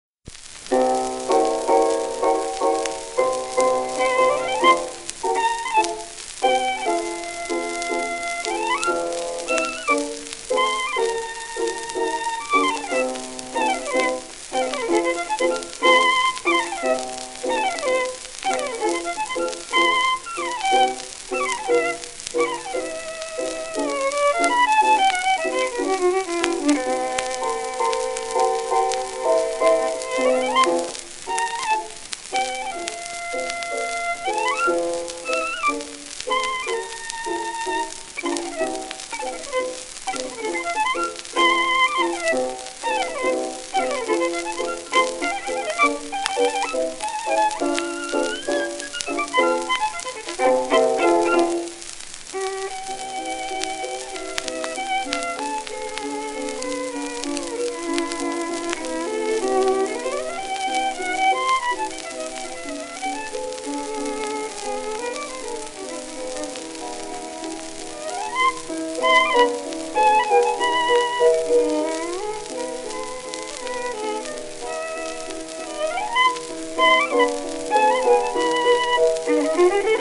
w/ピアノ
12インチ片面盤
盤質A-/B+ *小キズ(多少のクリック音あり),薄いスレ
1912年頃録音、80rpm
旧 旧吹込みの略、電気録音以前の機械式録音盤（ラッパ吹込み）